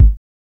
SOFT + MELLO.wav